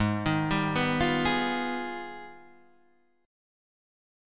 AbmM13 Chord
Listen to AbmM13 strummed